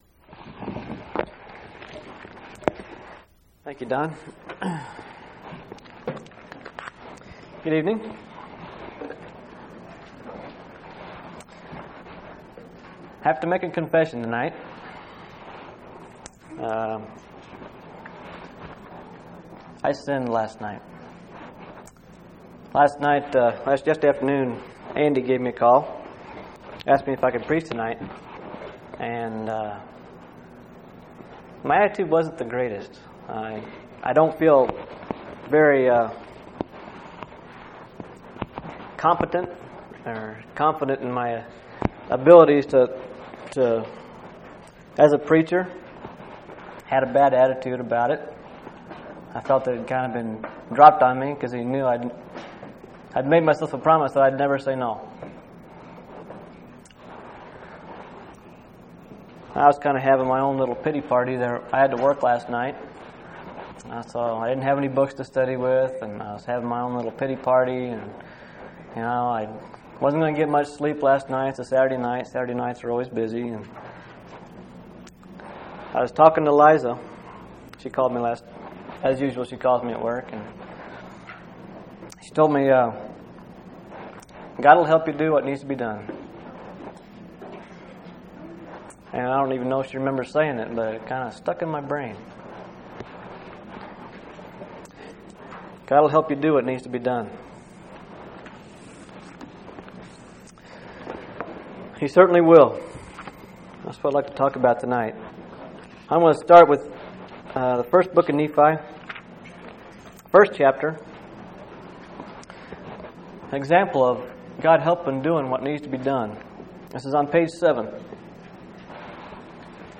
7/26/1998 Location: Phoenix Local Event